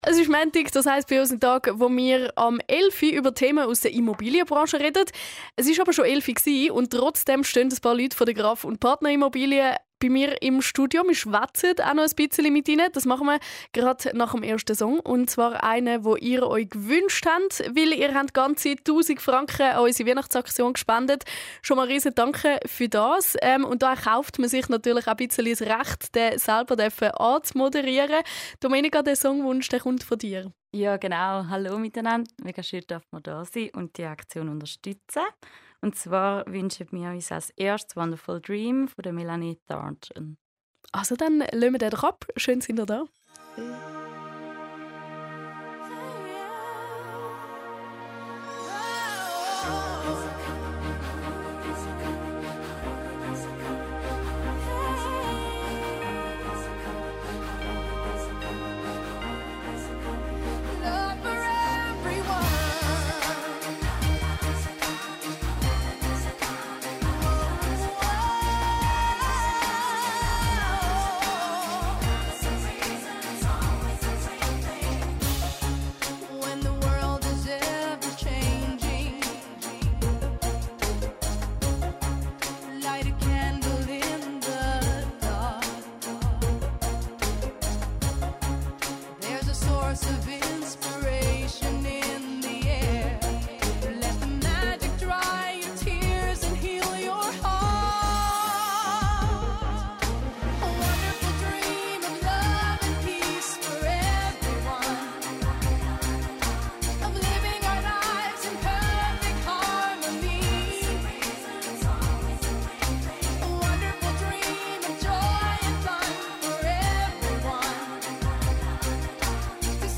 In der Vorweihnachtszeit durften wir mit dem gesamten Team von Graf & Partner Immobilien AG bei Radio Munot im Studio zu Gast sein. Anlass war die diesjährige Weihnachtsaktion «Zündschnur», welche Jugendliche in beruflichen Ausbildungen unterstützt und sich aktiv dafür einsetzt, Lehrabbrüche zu vermeiden.
Im Gespräch mit Radio Munot durften wir nicht nur unser Unternehmen vorstellen, sondern auch erläutern, weshalb uns das Engagement des Vereins Zündschnur besonders am Herzen liegt. Musikalisch begleitet wurde die Sendung von persönlichen Musikwünschen aus dem Team – ein stimmungsvoller Rahmen für einen besonderen Radiomoment zur Weihnachtszeit.